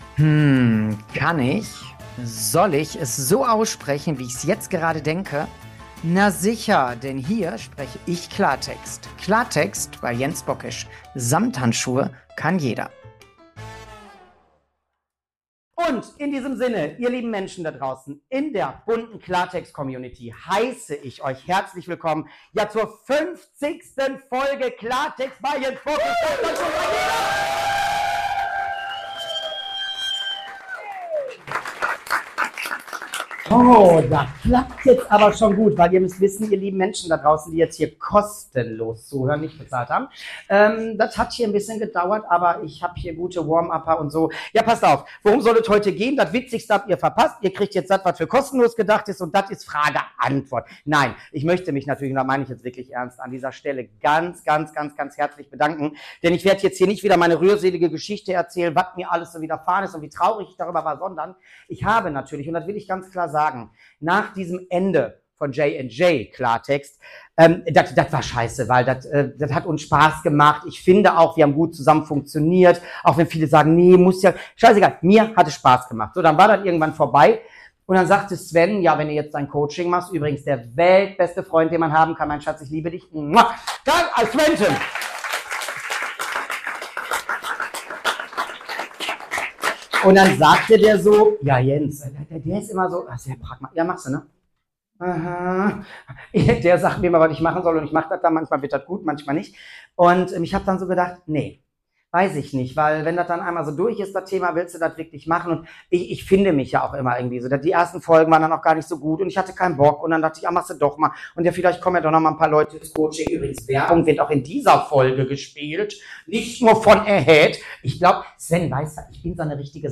Beschreibung vor 8 Monaten 50 Folgen Klartext – und diese wird besonders gefeiert: live, echt und mit ordentlich Herz! Bei der Jubiläumsfolge auf der Bühne mit Publikum durfte gelacht, nachgedacht und gefühlt werden.
Ein Abend voller Energie, Geschichten und Gänsehautmomenten – live für euch, jetzt zum Nachhören!